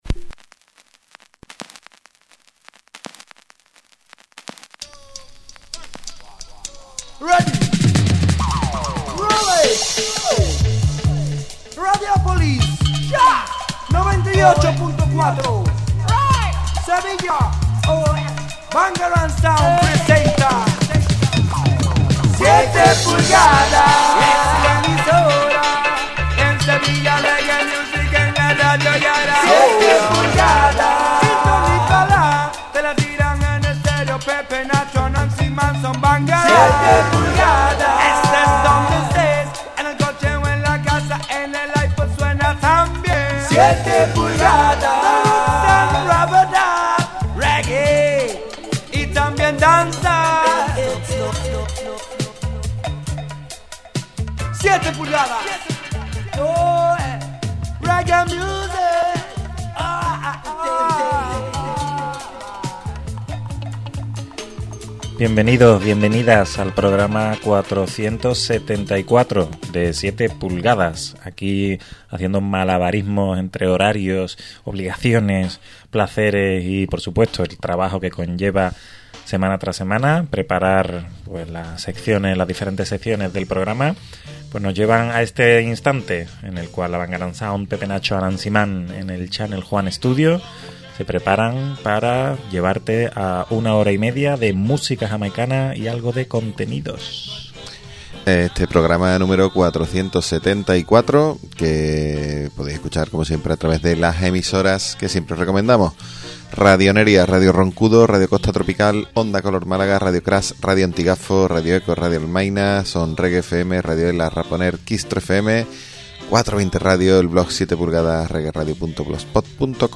Presentado y dirigido por la Bangarang Sound y grabado en Channel Juan Studio.